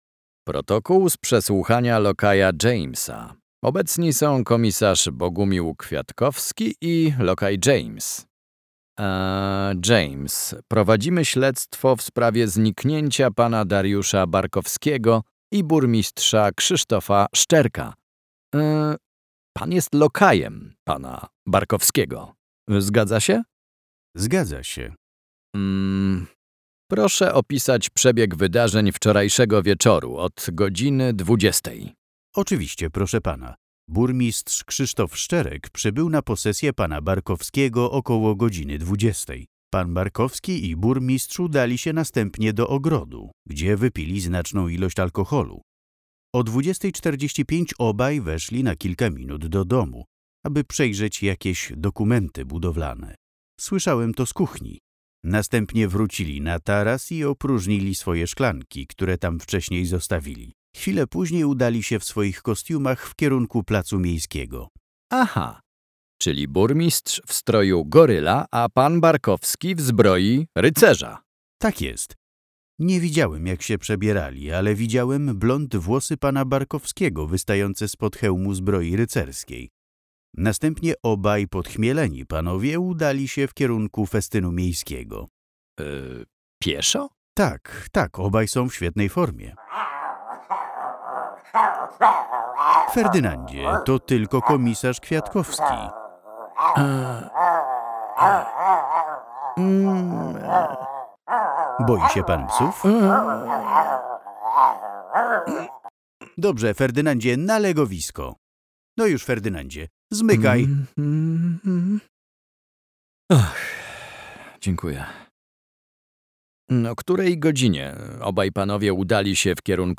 F11_pl_Fall_11_Polen_Verhoer_James.mp3